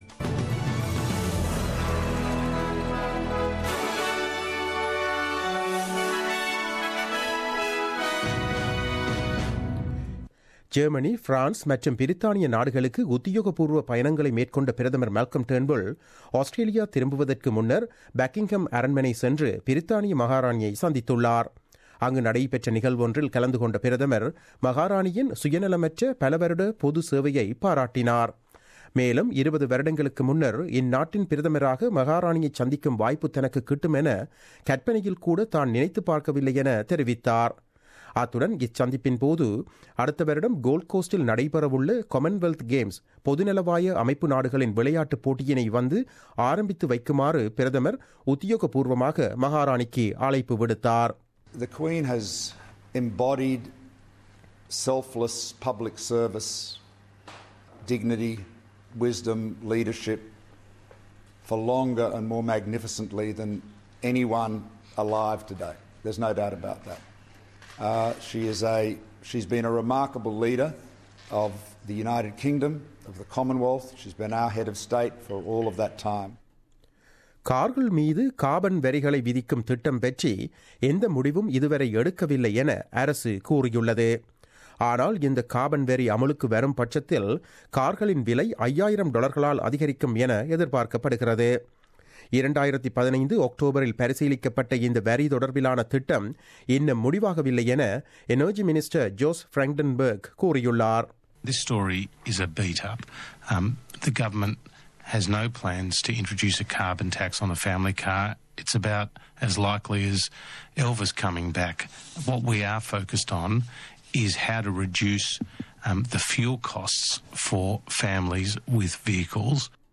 The news bulletin broadcasted on 12 July 2017 at 8pm.